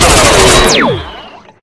rifle3.ogg